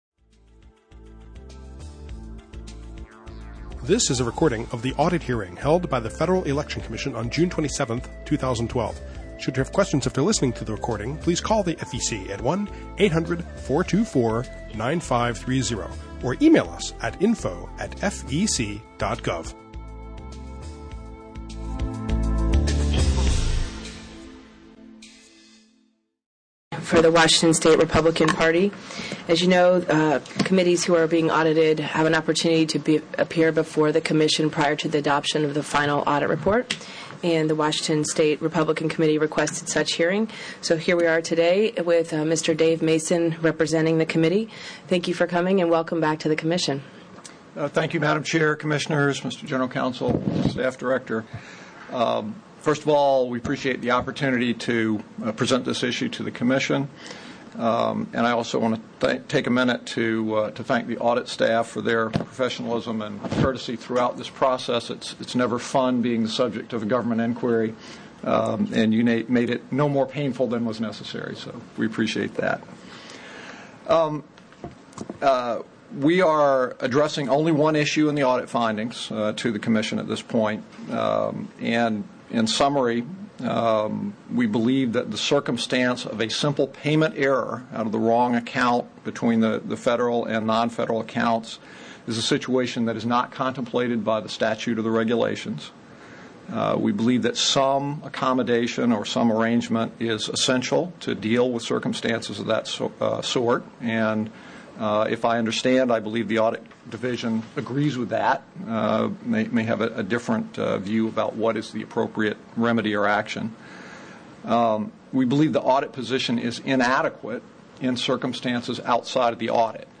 Open Meeting Agenda
Audio File of Entire Hearing, 10 MB